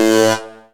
tekTTE63033acid-A.wav